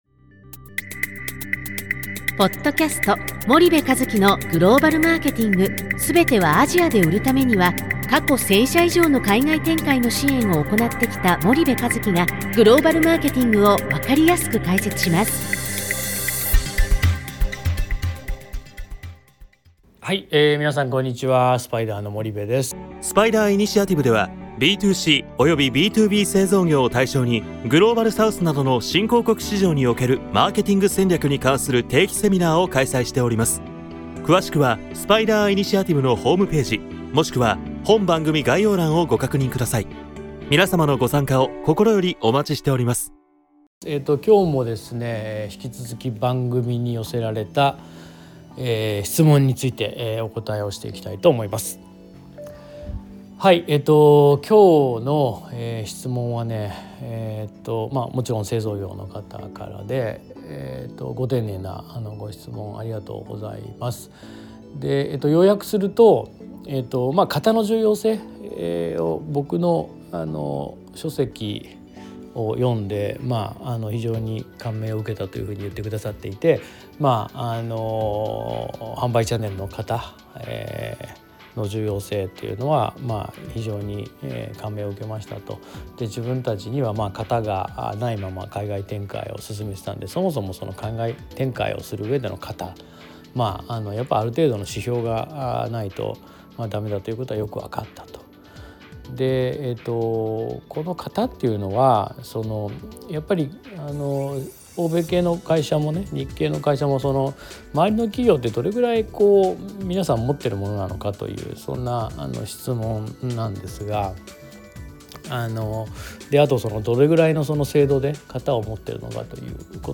ビジネスパーソンを対象に、アジア新興国を中心としたグローバルビジネスに関する様々なナレッジやノウハウを番組ナビゲーターと共にお届けしております。